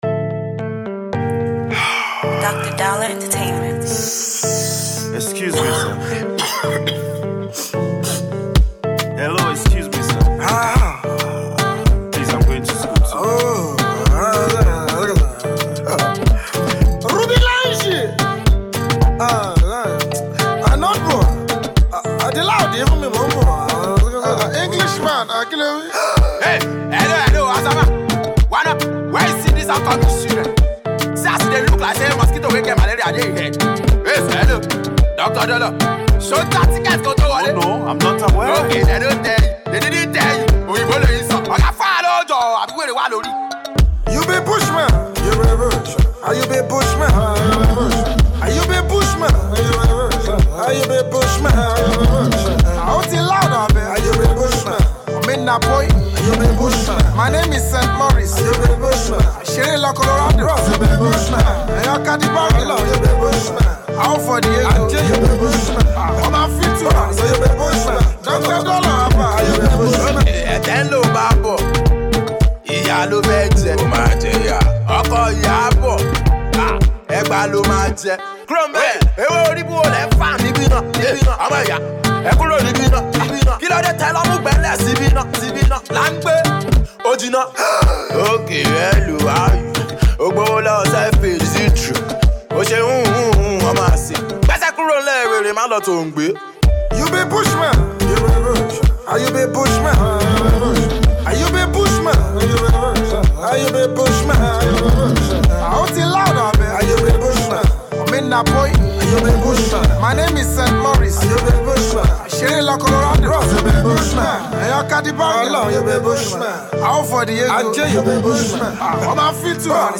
groovy single